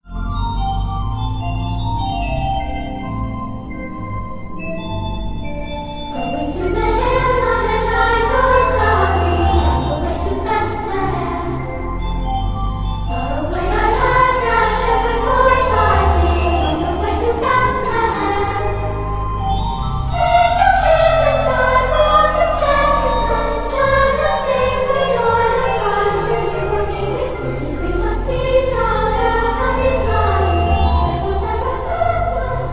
organ.